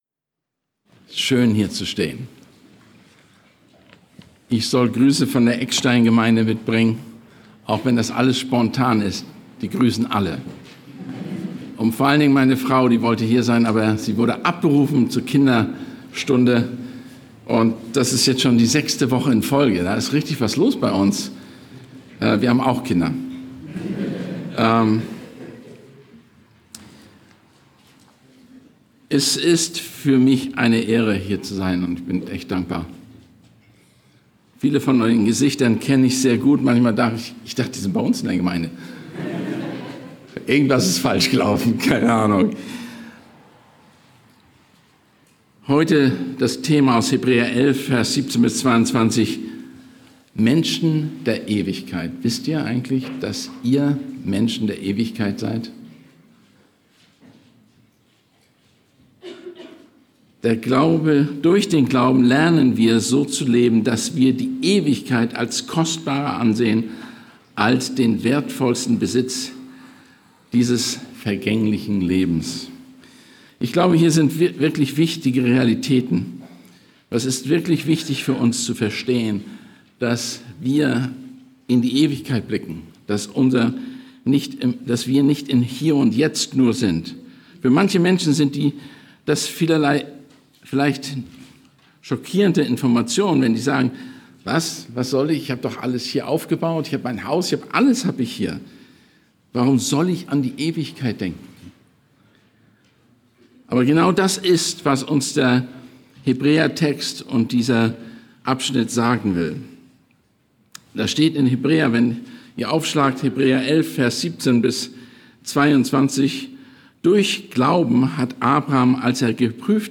Gastprediger